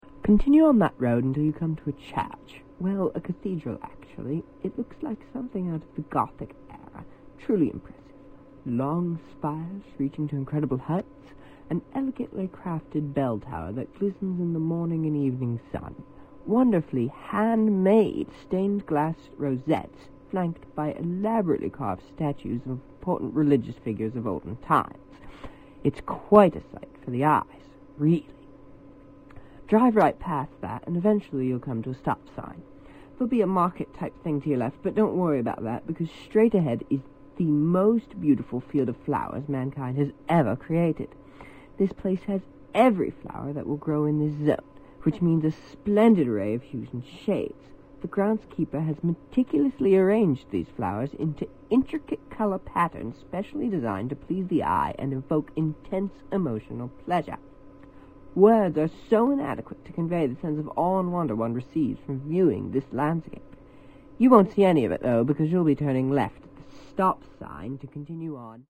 DirectionsReadAloud_48kbps.mp3